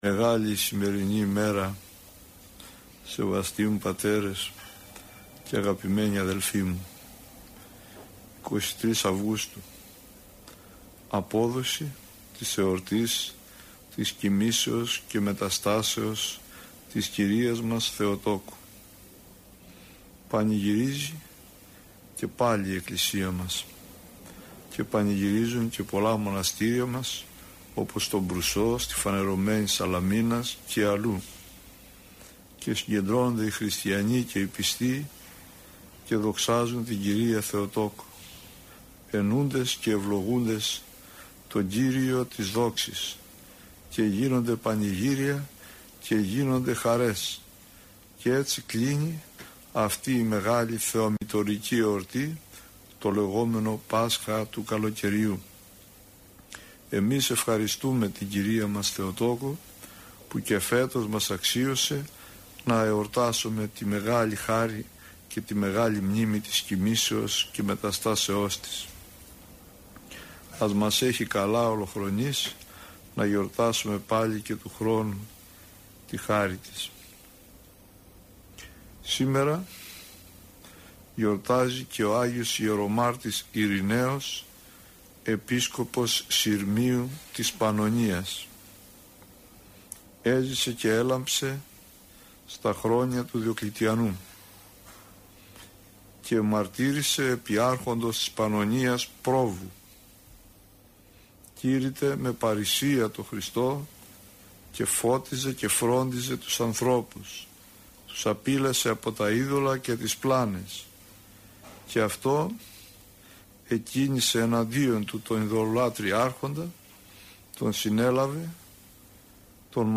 Πρόκειται για εκπομπή που μεταδόθηκε από τον ραδιοσταθμό της Πειραϊκής Εκκλησίας.